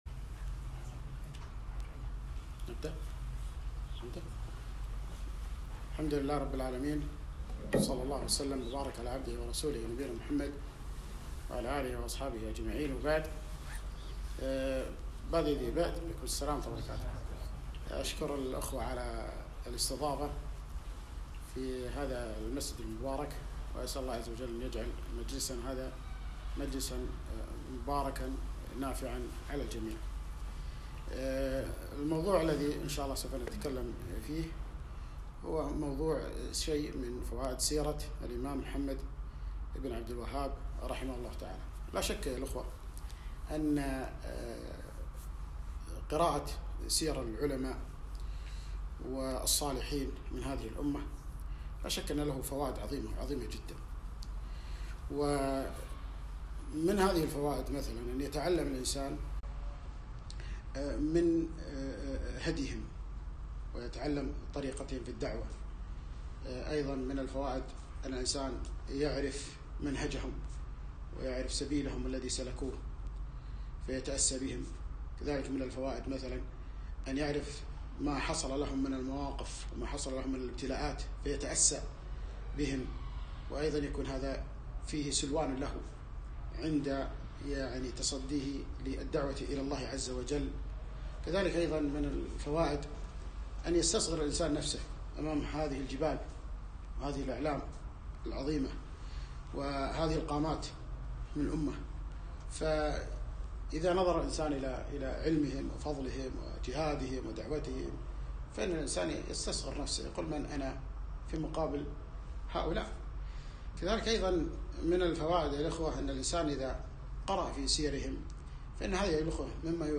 محاضرة- وقفات مع سيرة الإمام محمد بن عبدالوهاب - رحمه الله